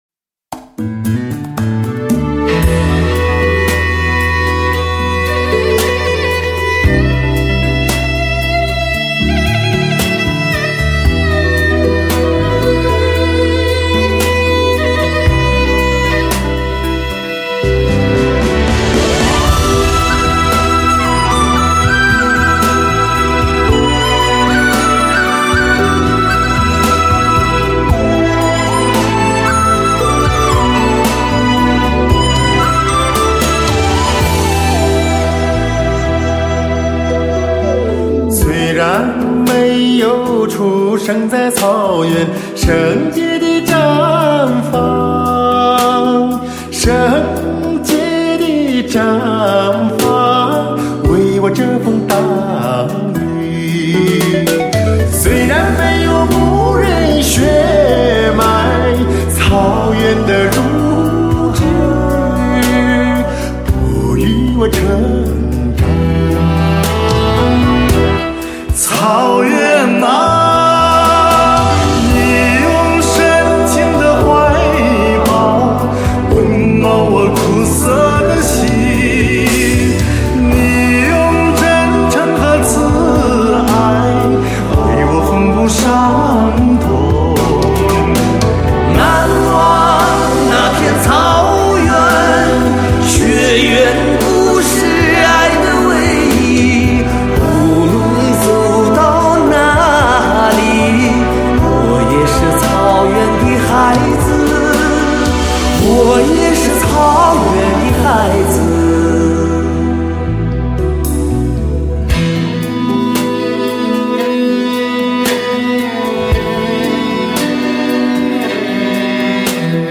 专辑格式：DTS-CD-5.1声道
悠扬的歌声划过苍穹，绽放在心间。